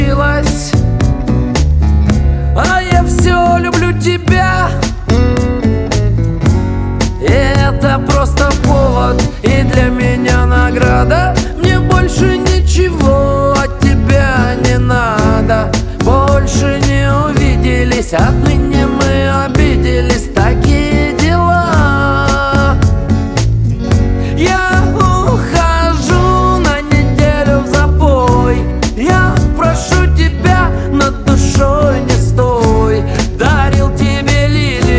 Жанр: Шансон / Русские
# Russian Chanson